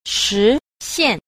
4. 實現 – shíxiàn – thực hiện
shi_xian.mp3